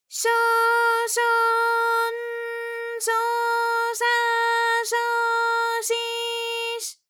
ALYS-DB-001-JPN - First Japanese UTAU vocal library of ALYS.
sho_sho_n_sho_sha_sho_shi_sh.wav